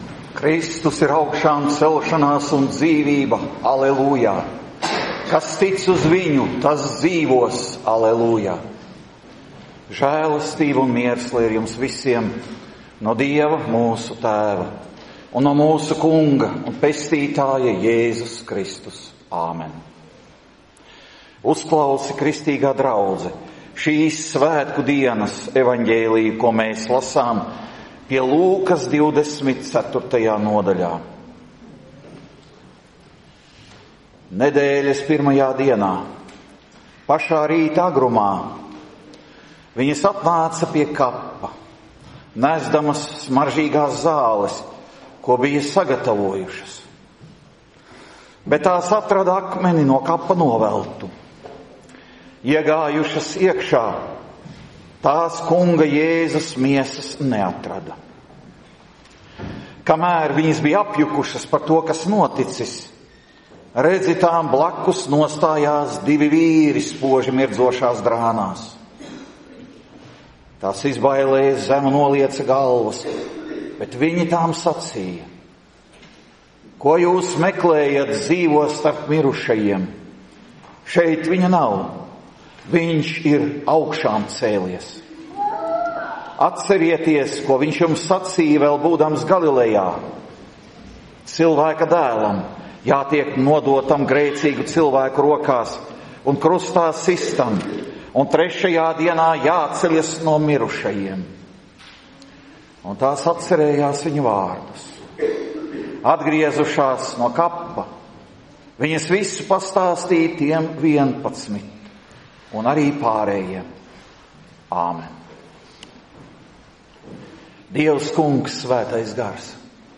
Svētdiena pēc Zvaigznes dienas | 13. janvāris